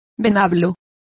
Complete with pronunciation of the translation of javelins.